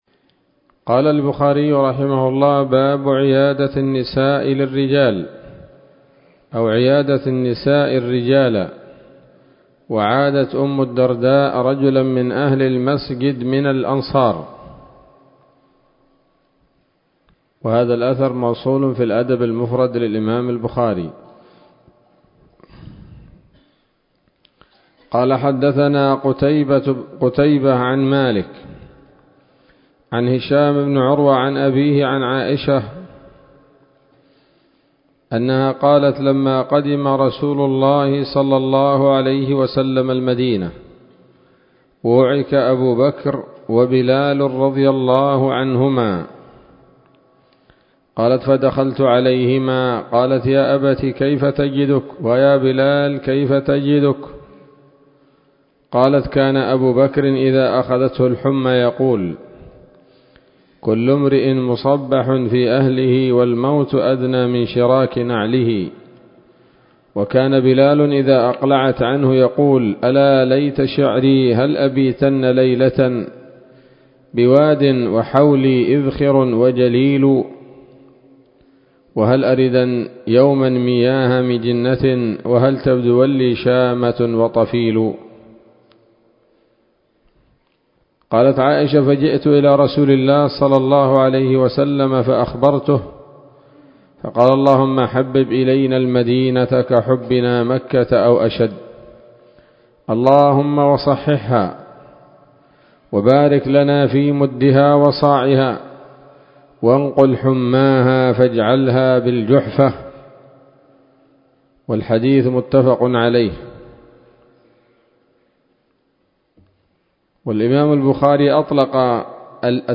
الدرس السابع من كتاب المرضى من صحيح الإمام البخاري